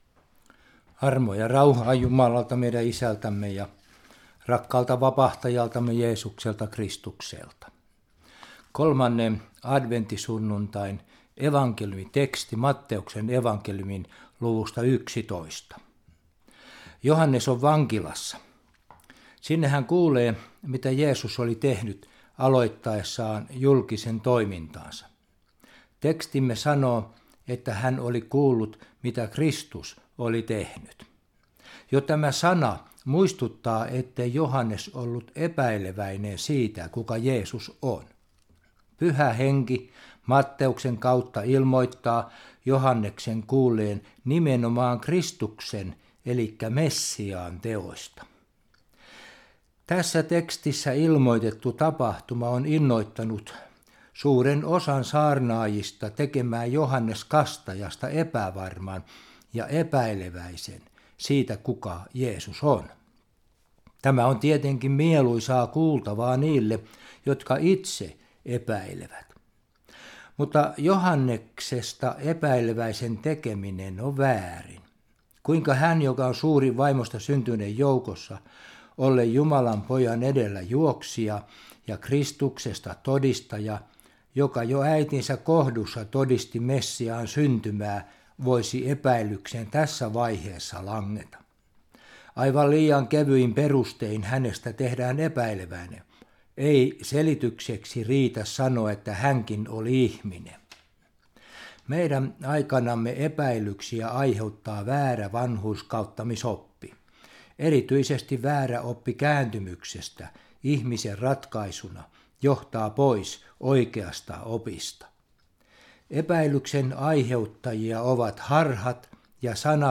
Evankeliumisaarna Järviradioon